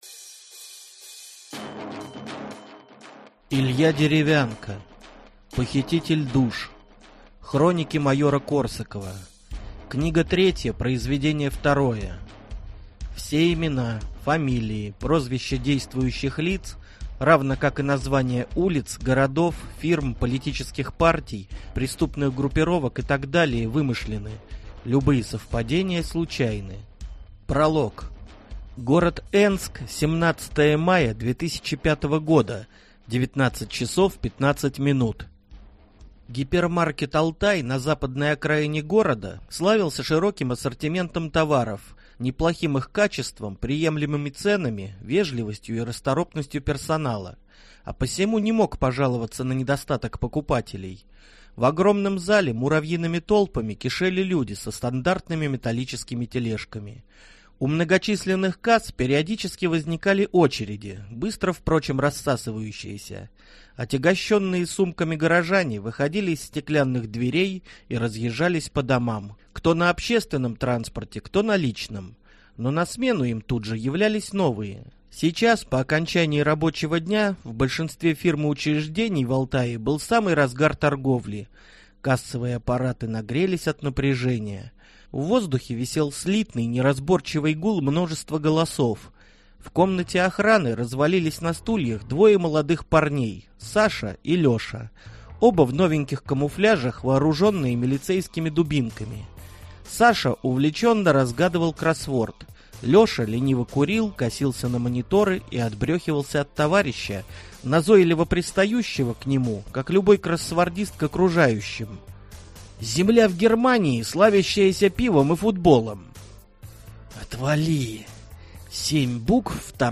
Аудиокнига Похититель душ | Библиотека аудиокниг
Прослушать и бесплатно скачать фрагмент аудиокниги